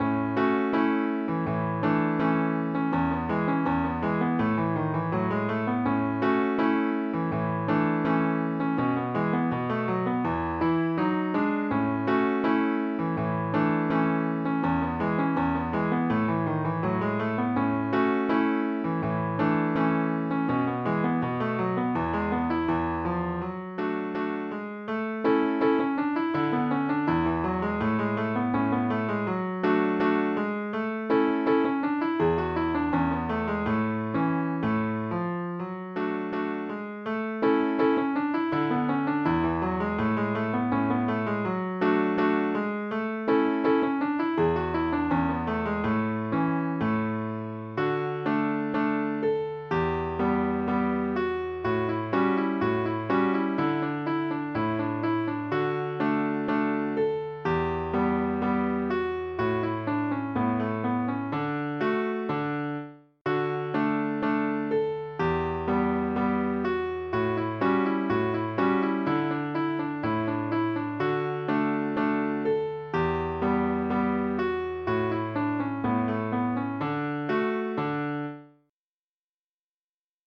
Schottich[tab